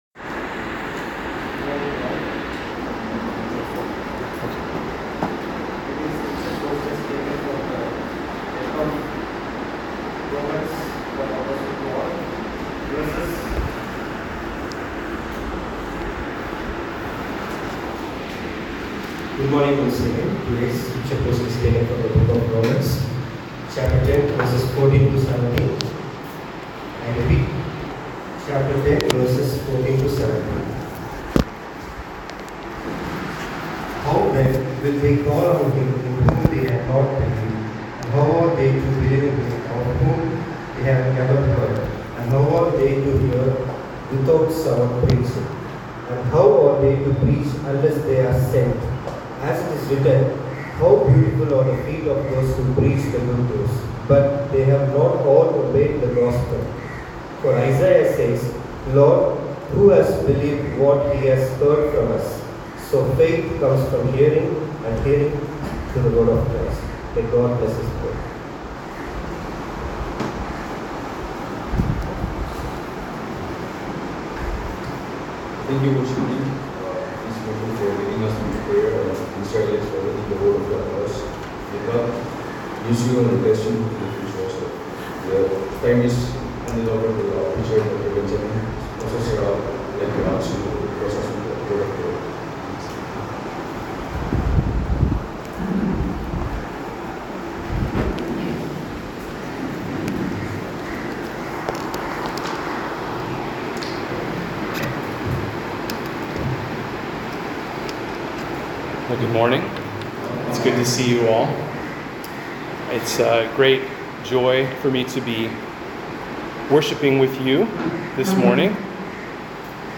This is the audio (27:07, 23.7 MB) of a sermon I preached at a chapel service for students and faculty in Chennai, Tamil Nadu, on March 18, 2025. The Scripture text is Romans 10:14–17, which was read before I stood up to speak so it's not on the recording.